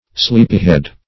Sleepyhead \Sleep"y*head`\, n.